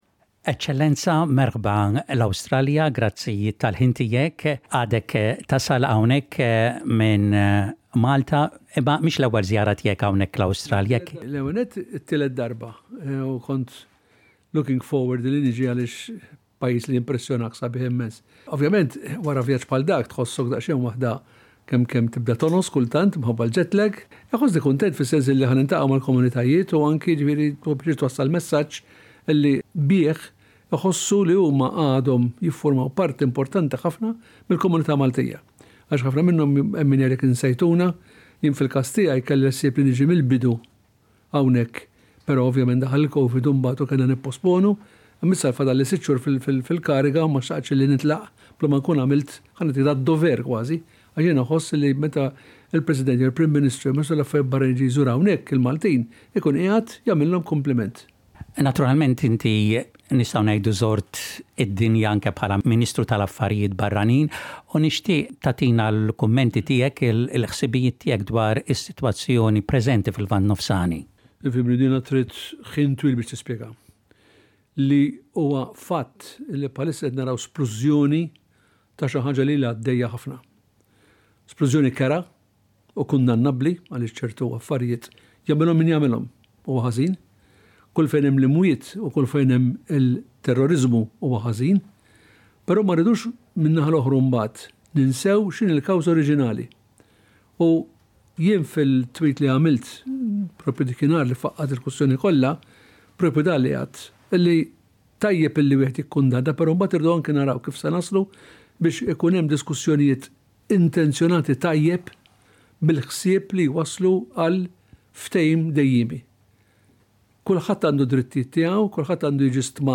In his interview President Vella delivers a message to the Maltese in Australia, in which he appeals to maintain the Maltese language and culture.